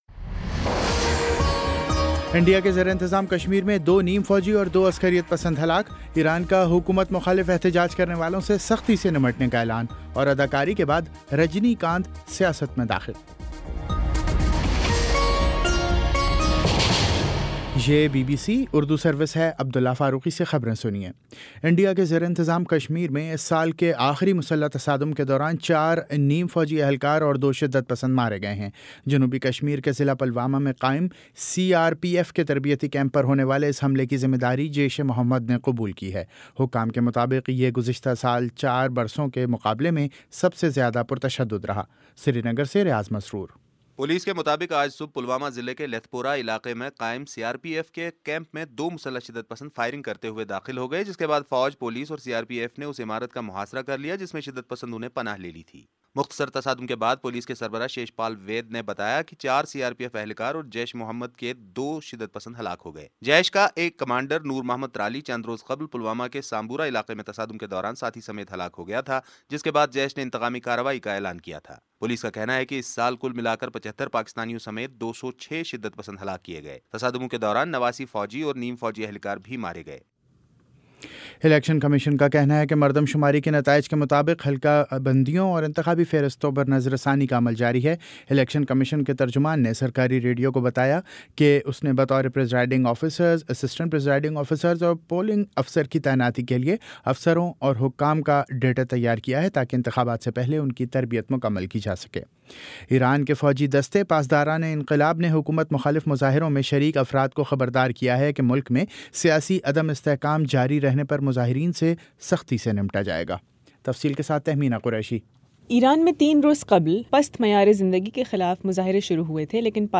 دسمبر 31 : شام پانچ بجے کا نیوز بُلیٹن